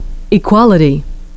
e’quality <)), equalization <)), equilit’arian <)), we find the stress shifting from one syllable to another as the word gets longer.